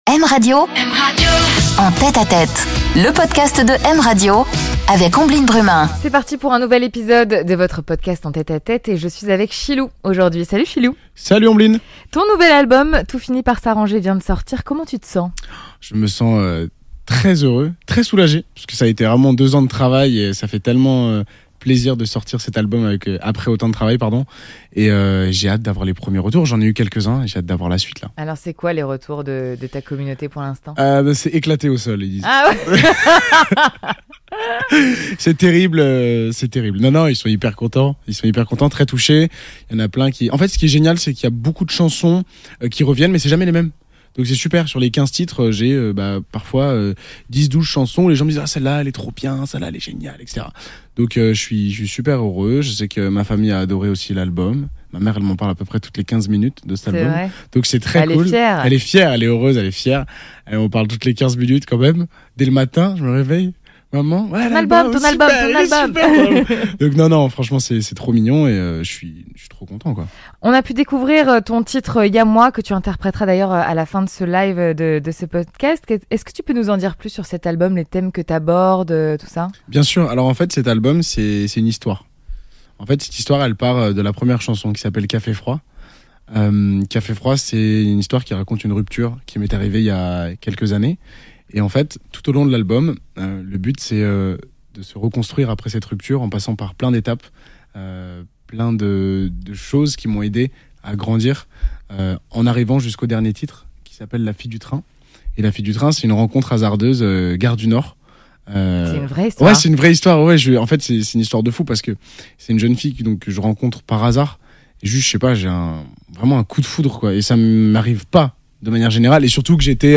Il nous interprète aussi son titre en live !